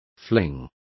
Complete with pronunciation of the translation of flings.